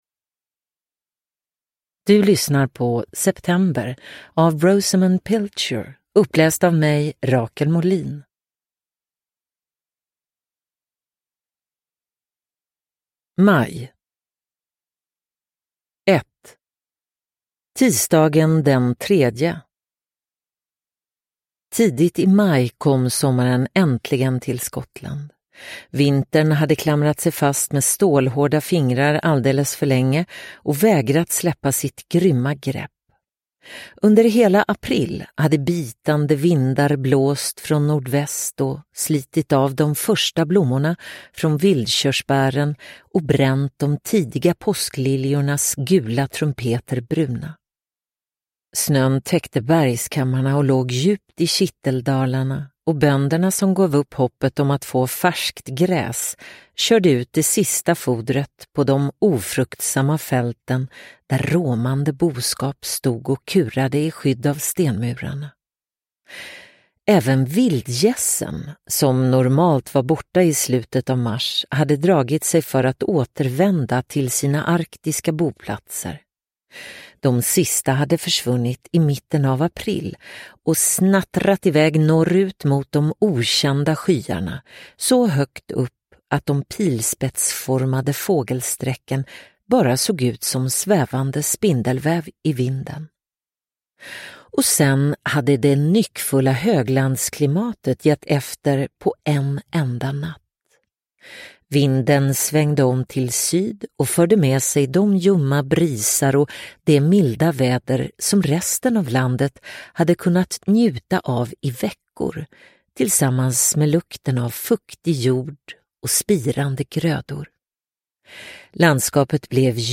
September – Ljudbok – Laddas ner